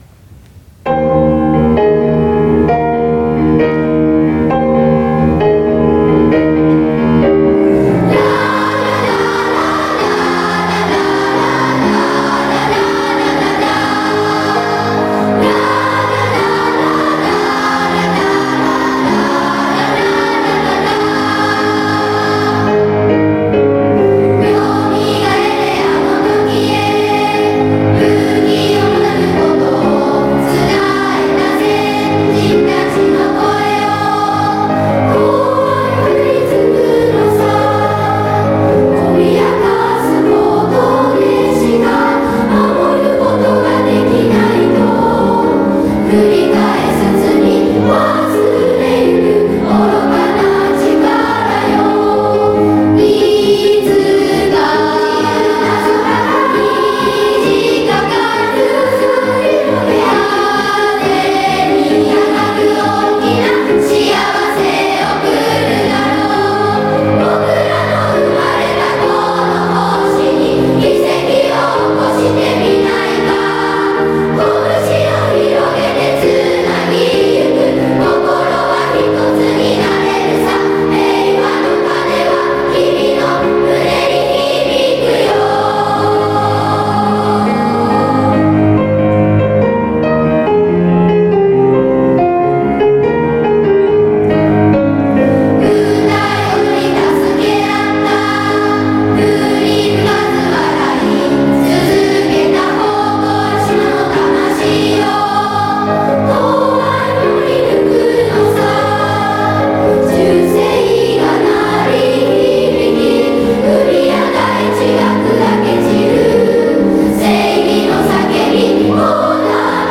うつのみやジュニア芸術祭合唱祭